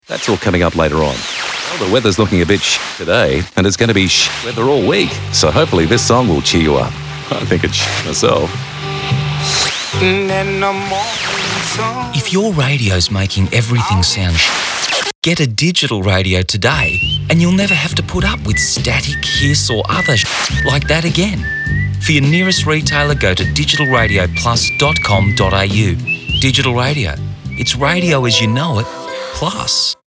A radio ad to promote digital radio has been let off by the Ad Standards Bureau for supposedly using foul language.
The ad watchdog threw out a complaint made against a spot for Commercial Radio Australia, where a series of weather announcements are distorted by radio static due to poor reception.
The complaint read: “Although semi- bleeped out by a mis-tuned radio sound, the use of the word shit is quite clear as only the ‘I’ sound is omitted. It is repeated several times.”